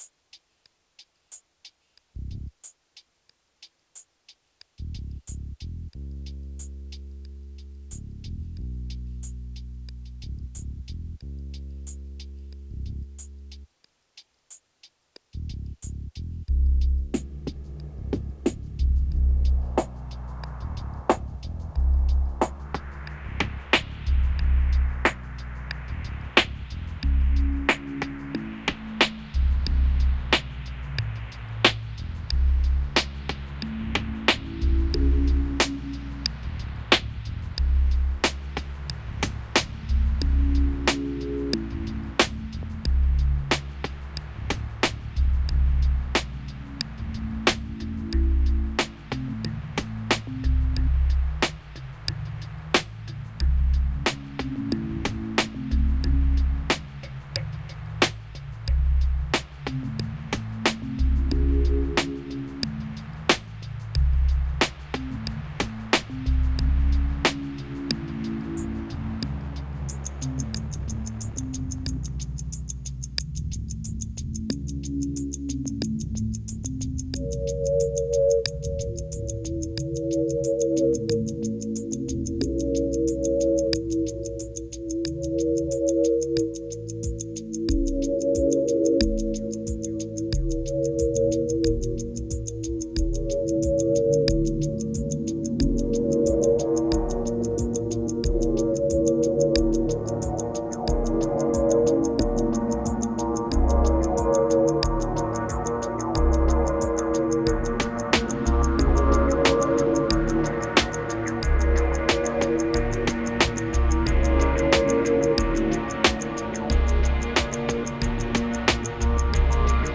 2216📈 - 76%🤔 - 91BPM🔊 - 2017-06-16📅 - 531🌟